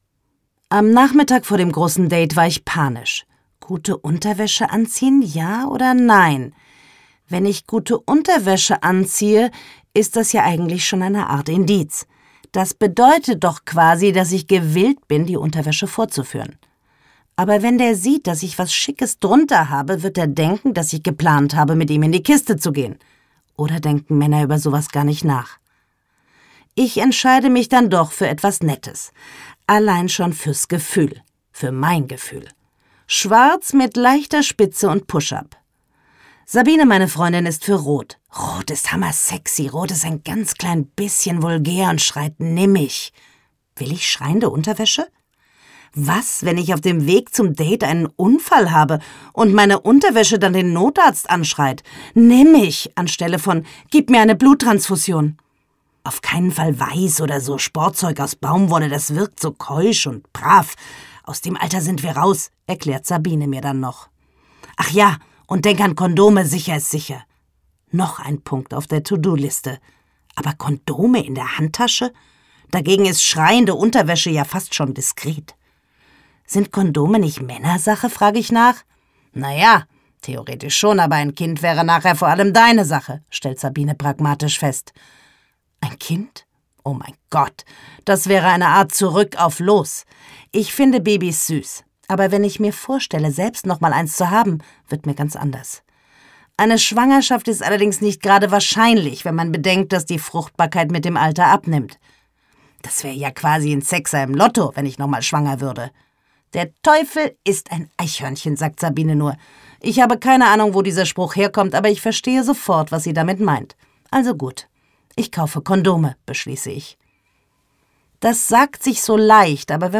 Gekürzt Autorisierte, d.h. von Autor:innen und / oder Verlagen freigegebene, bearbeitete Fassung.
Aufgebügelt Gelesen von: Susanne Fröhlich